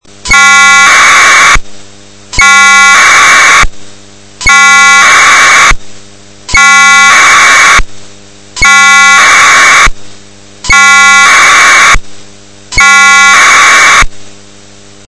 AFSK SRLL 2.0のサンプルサウンドを用意いたしました．
AFSK SRLL 2.0 Sample Sound(64Bytes)
AFSK_SRLL2_SampleSound.mp3